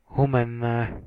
Sk-Humenne.ogg.mp3